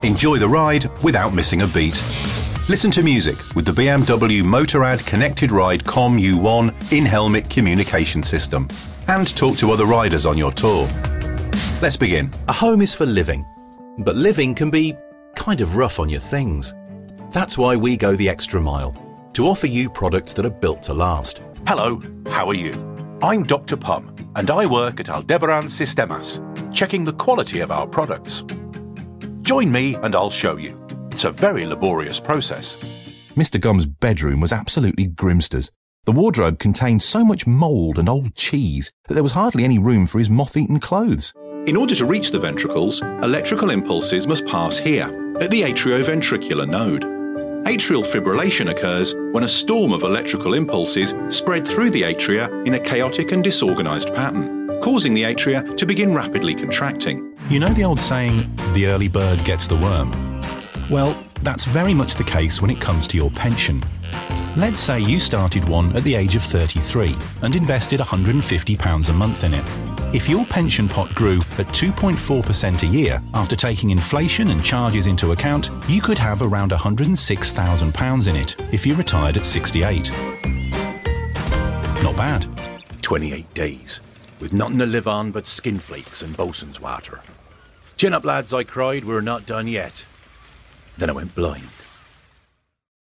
English (British)
Conversational
Friendly
Clear